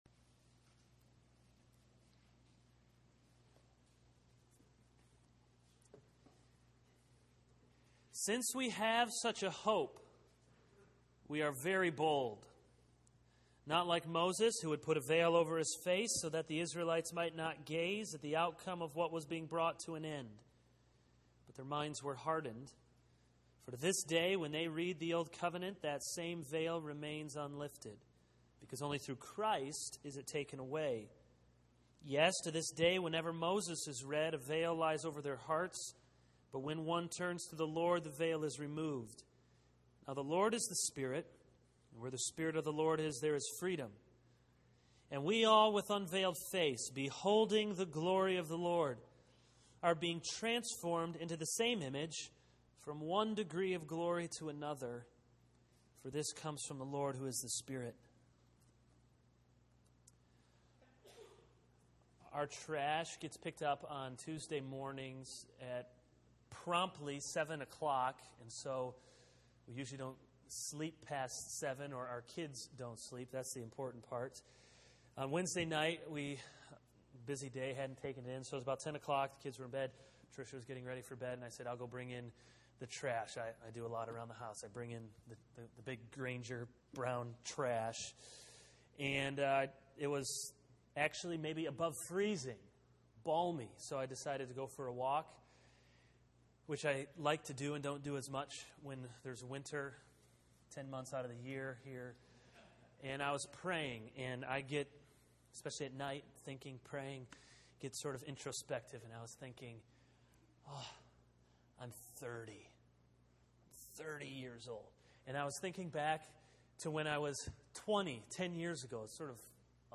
This is a sermon on 2 Corinthians 3:12-18.